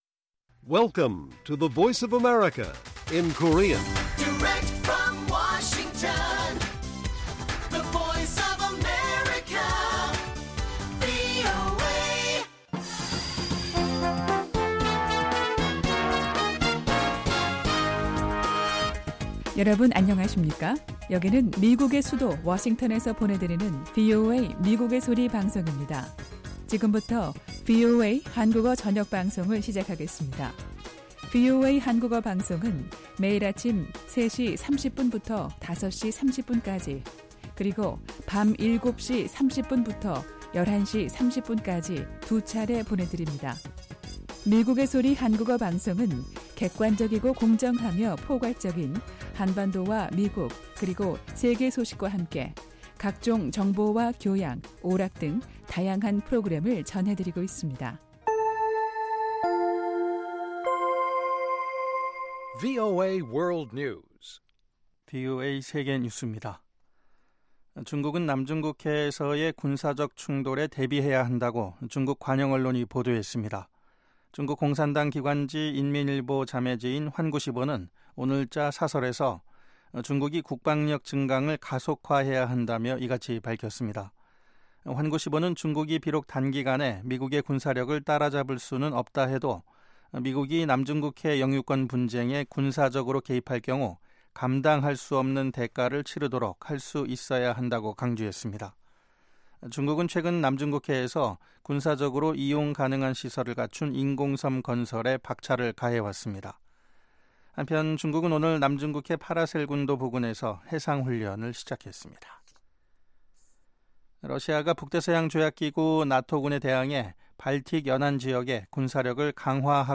VOA 한국어 방송의 간판 뉴스 프로그램 '뉴스 투데이' 1부입니다. 한반도 시간 매일 오후 8:00 부터 9:00 까지, 평양시 오후 7:30 부터 8:30 까지 방송됩니다.